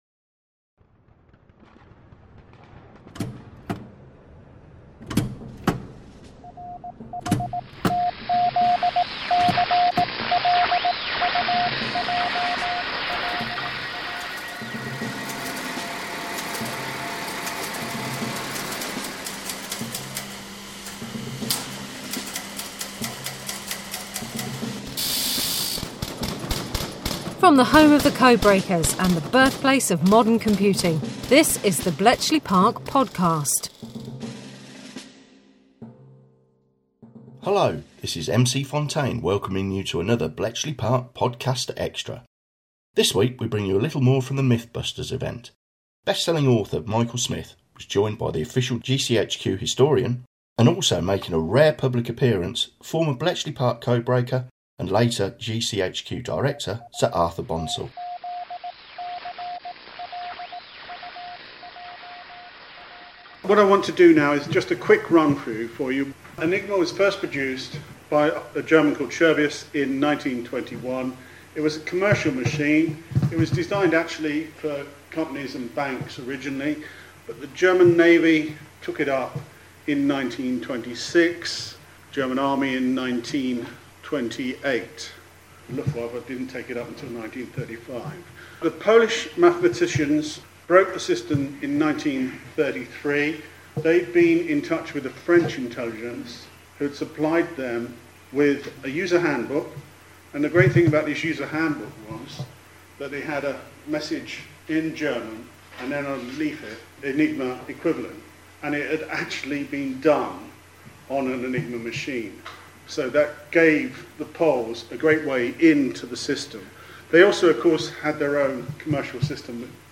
This week we have more content from the Mythbusters event held at Bletchley Park.